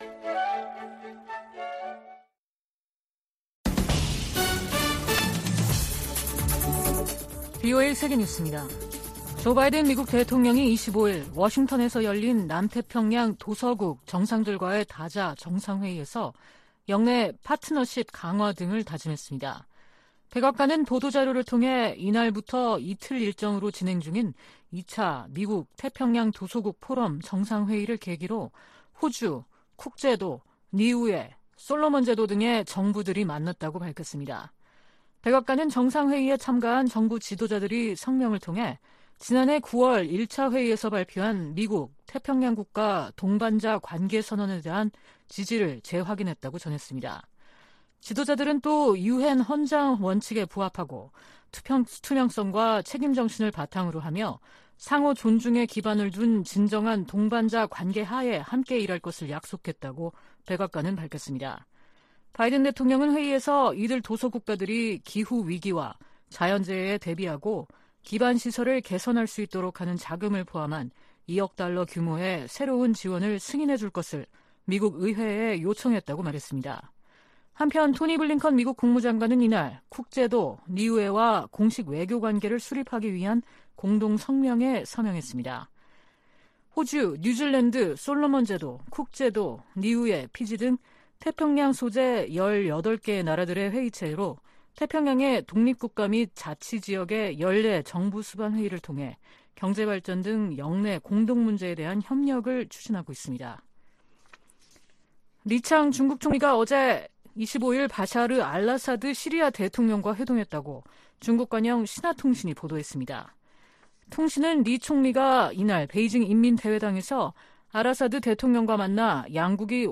VOA 한국어 아침 뉴스 프로그램 '워싱턴 뉴스 광장' 2023년 9월 27일 방송입니다. 토니 블링컨 미 국무장관은 미한 동맹이 안보 동맹에서 필수 글로벌 파트너십으로 성장했다고 평가했습니다. 한국과 중국, 일본의 외교당국은 3국 정상회의를 빠른 시기에 개최하기로 의견을 모았습니다.